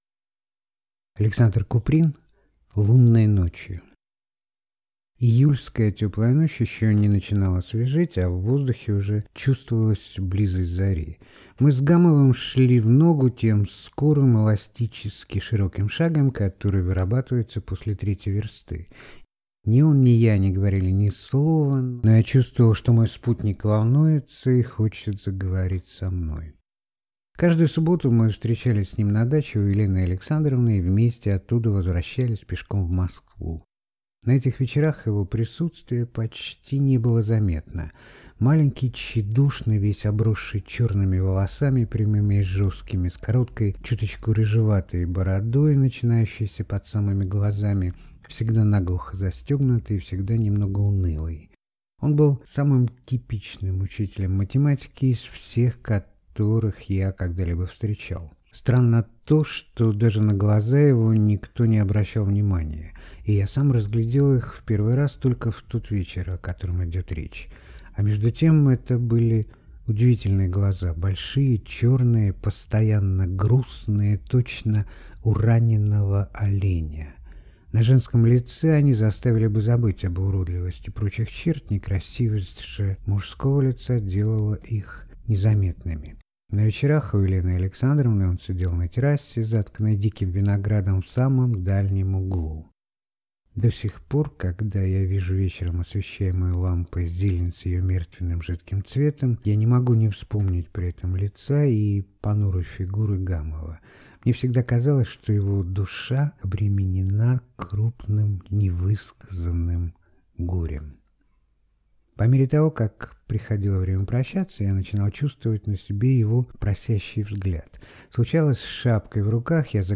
Аудиокнига Лунной ночью | Библиотека аудиокниг